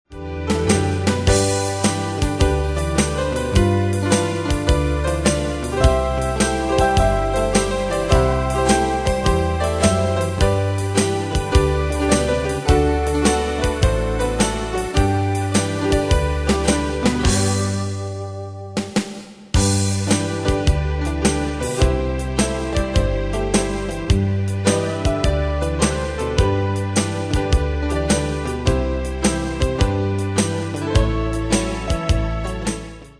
Style: Country Ane/Year: 1958 Tempo: 105 Durée/Time: 2.29
Danse/Dance: TwoSteps Cat Id.